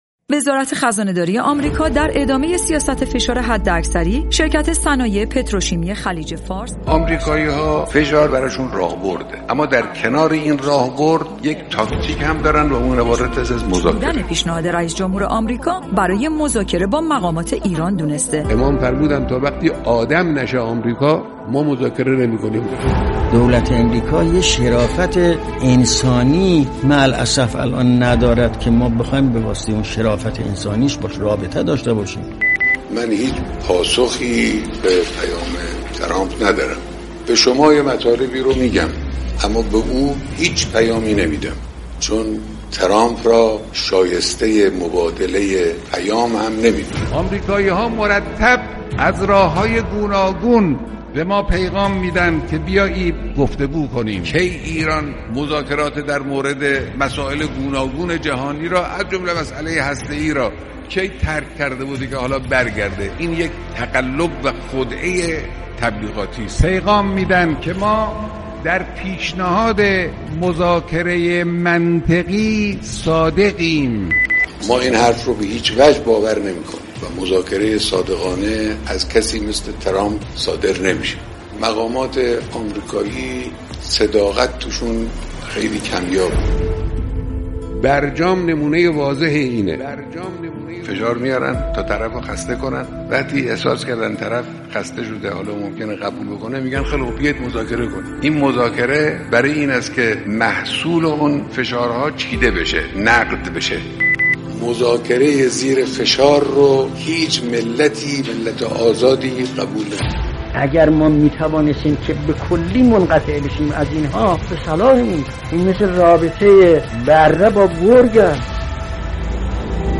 صوت/ "تاکتیک آمریکایی" در بیان رهبر انقلاب